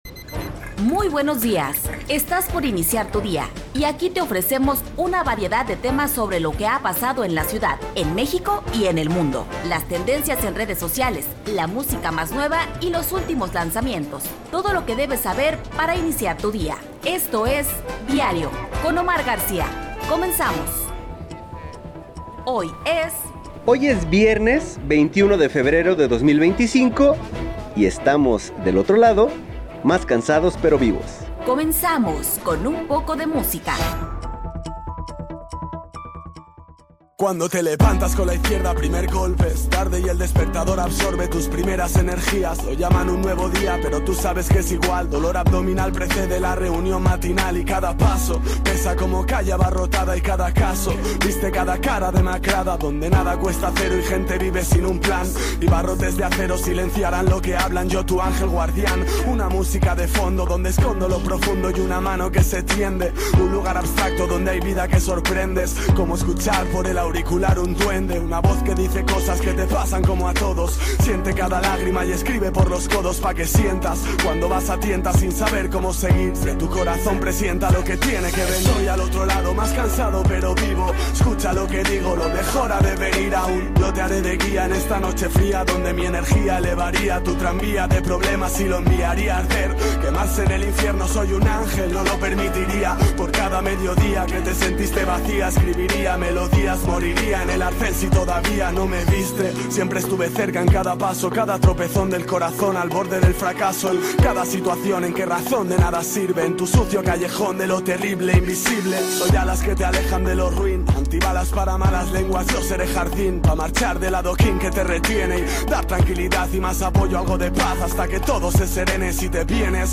Revista Informativa de Radio Universidad de Guadalajara